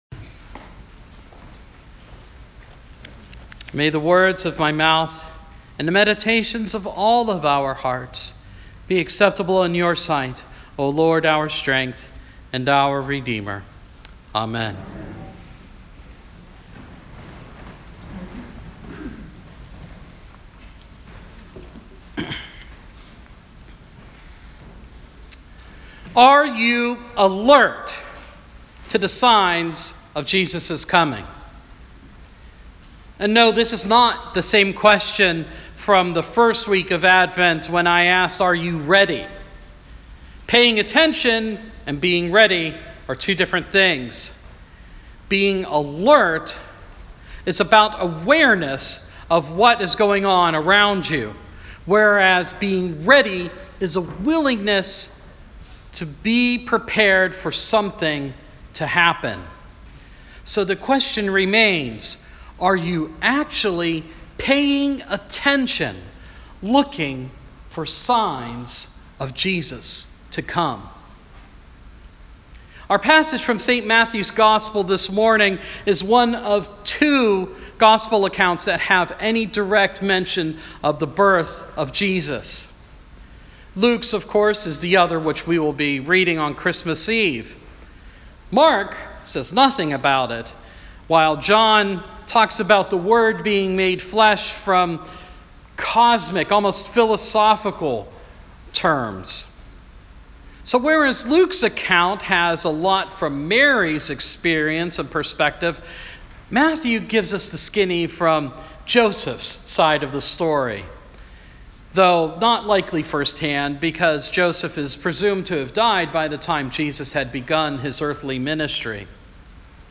Fourth Sunday in Advent – 2019